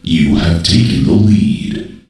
takenlead.ogg